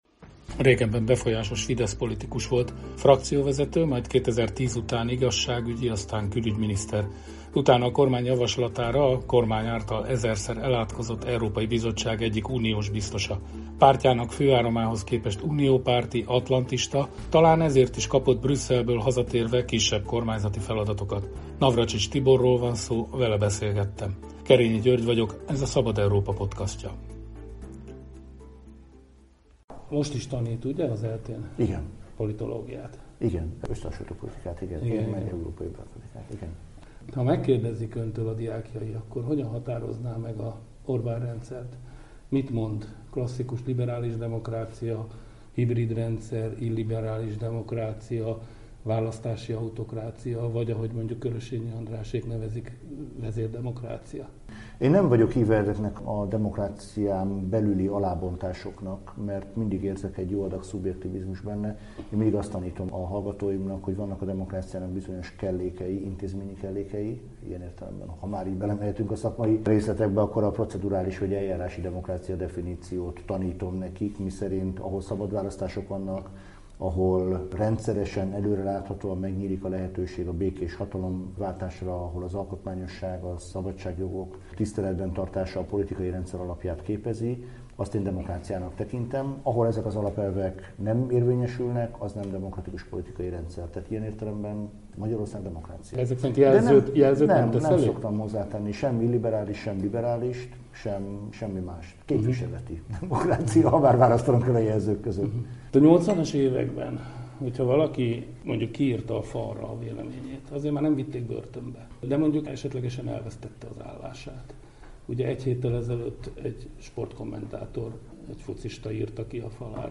"Magyarország demokrácia" – interjú Navracsics Tiborral